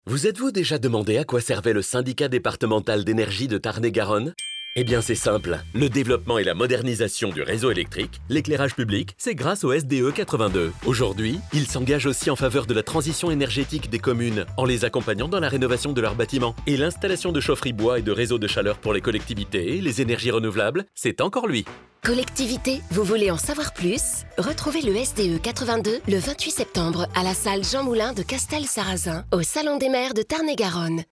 Toute cette semaine, un spot radio expliquant les compétences du SDE 82 est diffusé sur radio Nostalgie !